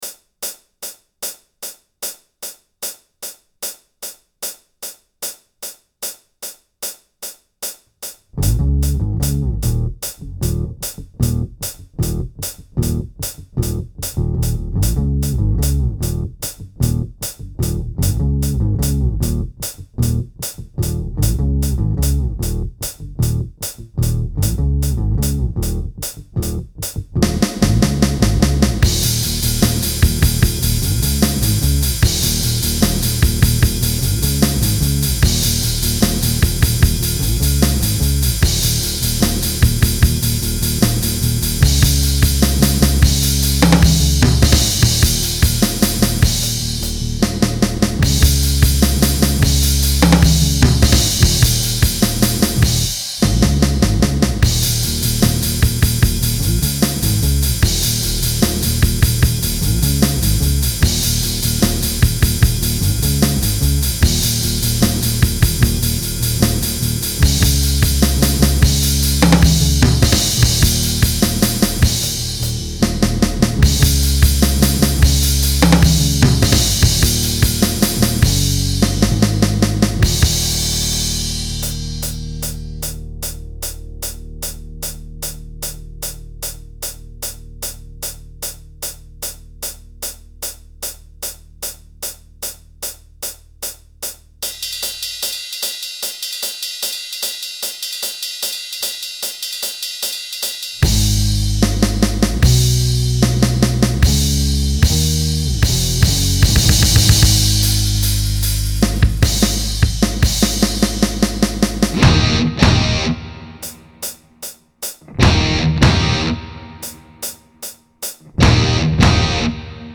You be the main guitar 1 part
Tip: The riff enters 2nd beat of the 2nd measure.